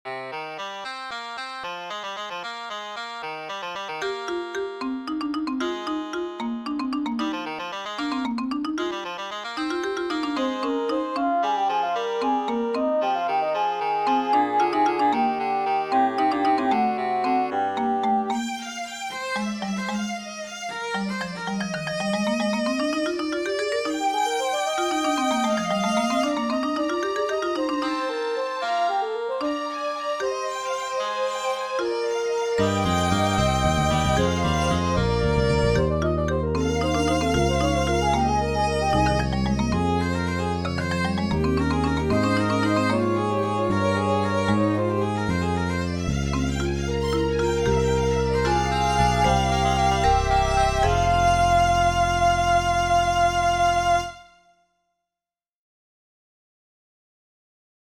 Siendo estudiante, utilicé el secuenciador para aprenderme cada una de las voces de una fuga para órgano que estaba practicando: grabé las cinco voces y les asigné diferente timbre e incluso diferente localización en el campo de sonido estéreo.
Fuga de órgano «orquestada» con un secuenciador
fugaorquestada.mp3